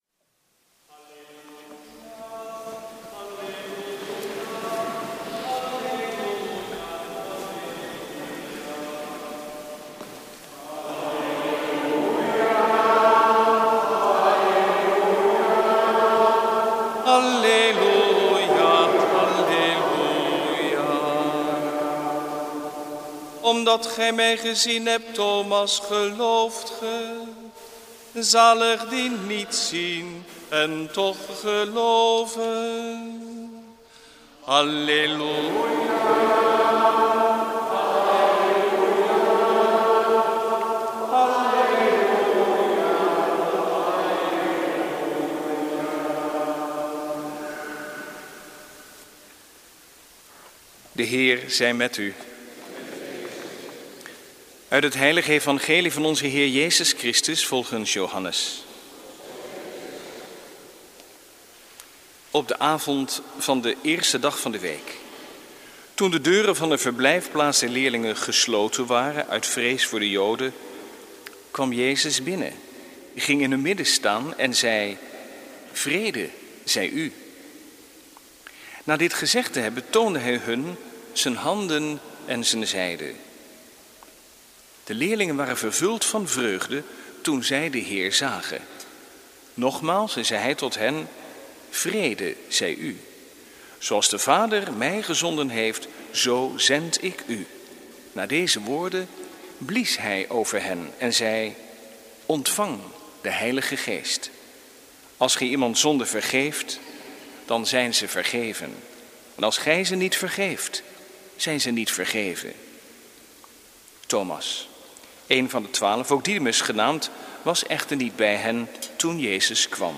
Eucharistieviering beluisteren vanuit De Goede Herder (MP3)